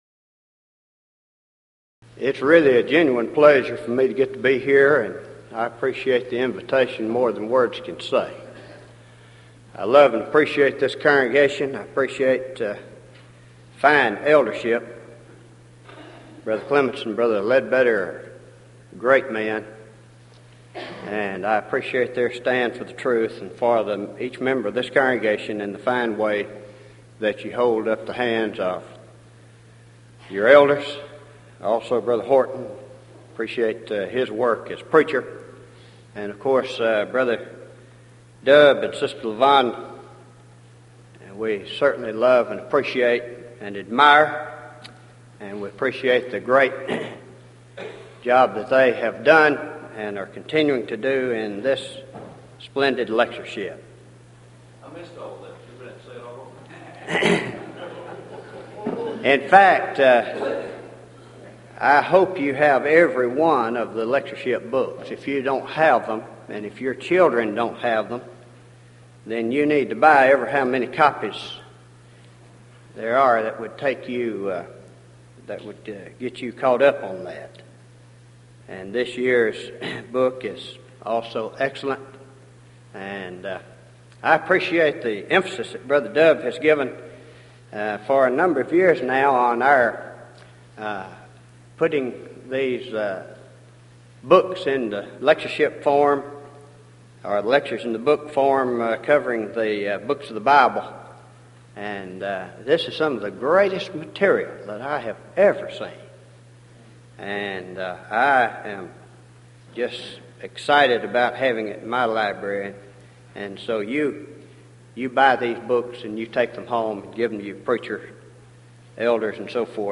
Title: DISCUSSION FORUM: The Worship Of God -- Our Way Of God's Way?